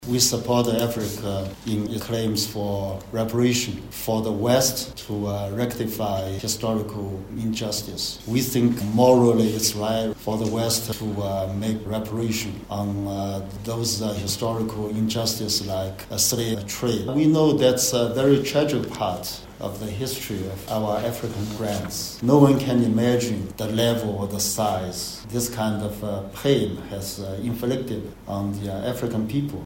The Chinese government has voiced its support for African nations’ calls for reparations over historical injustices such as the transatlantic slave trade. Speaking in Windhoek, Chinese Ambassador to Namibia, Zhao Weiping, said the West must acknowledge and act upon the suffering inflicted on Africans, describing reparations as a long-overdue act of justice. He added that China is aligning its support with Namibia’s development priorities, pledging continued investment to help realise the government’s goal of creating 500 000 jobs in five years.